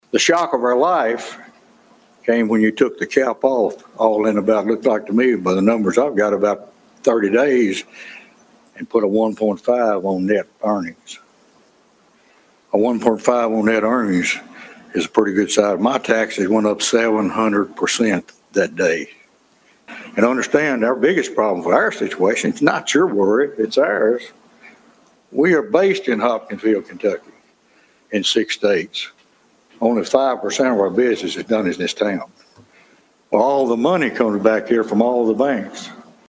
Agriculture leaders and related industries addressed Hopkinsville City Council about the issue Tuesday night.